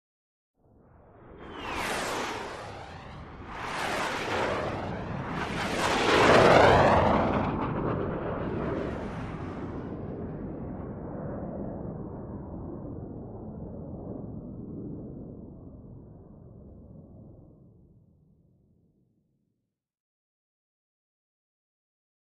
F-5: By Series Of 4: High; F-5 Formation, Close And Distant Flybys. Medium To Distant Perspective. Jet.